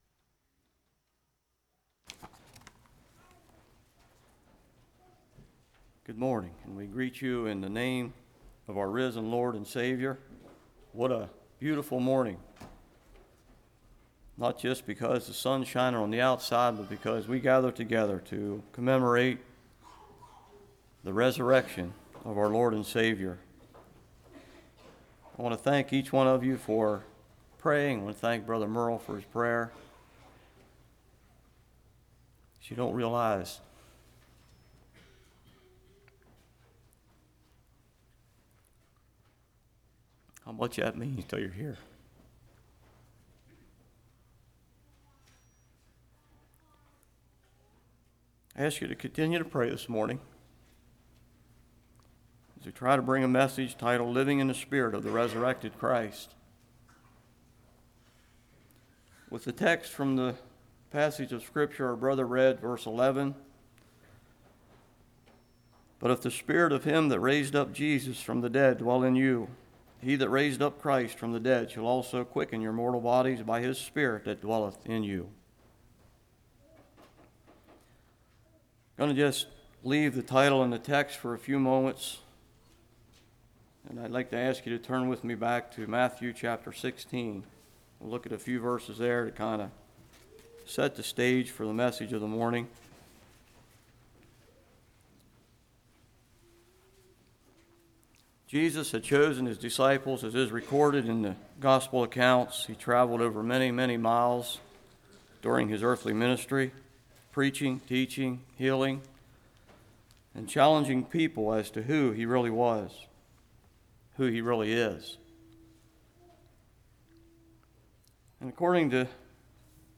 Romans 8:1-17 Service Type: Morning Purpose